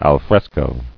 [al·fres·co]